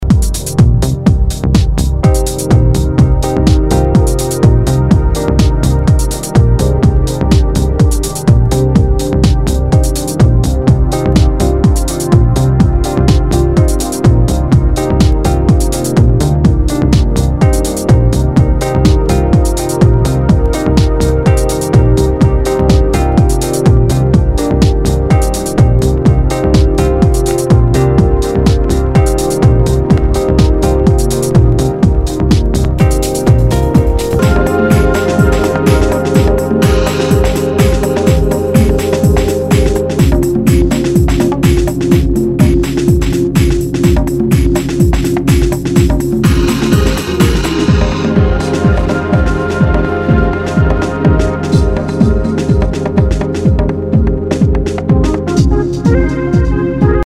HOUSE/TECHNO/ELECTRO
ナイス！ディープ・ハウス！
[VG ] 平均的中古盤。スレ、キズ少々あり（ストレスに感じない程度のノイズが入ることも有り）